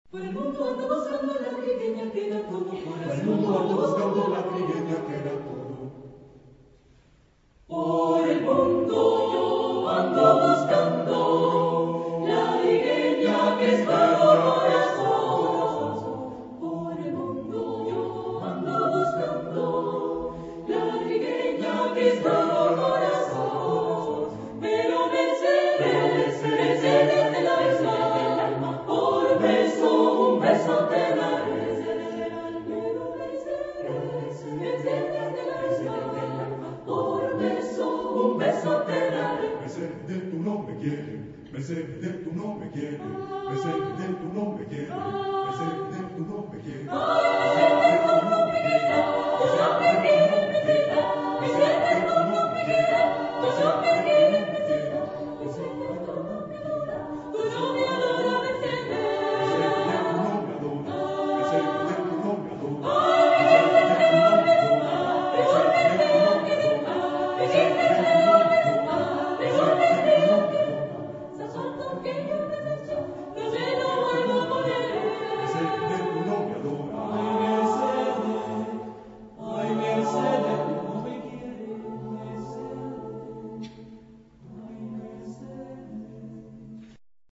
Género/Estilo/Forma: Profano ; Canción
Tipo de formación coral: SAATB  (5 voces Coro mixto )